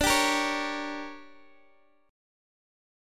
DM7sus4#5 chord